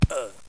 GRUNT4.mp3